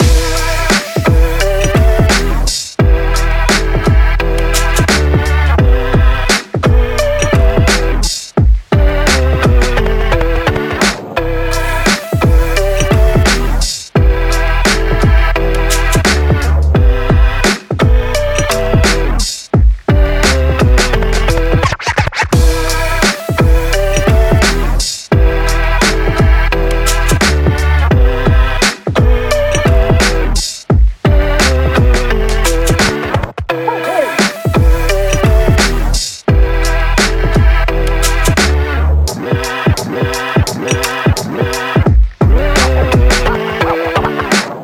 BPM86、Em/Gmaj、同じドラムセット縛りの6パターンのビート・トラックです。
EMO HIPHOP LOOP TRACK BPM86 Em/Gmaj pattern F